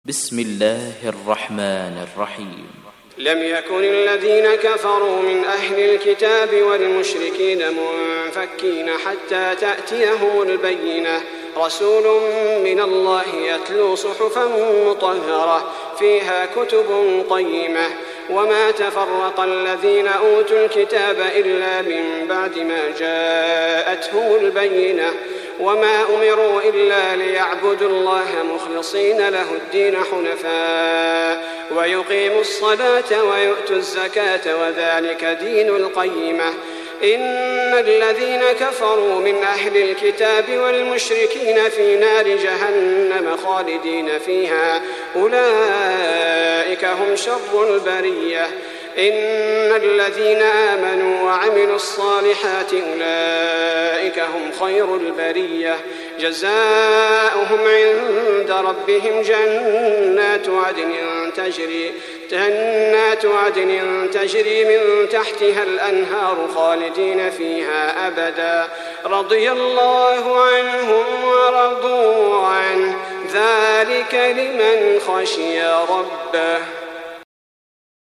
تحميل سورة البينة mp3 بصوت صلاح البدير برواية حفص عن عاصم, تحميل استماع القرآن الكريم على الجوال mp3 كاملا بروابط مباشرة وسريعة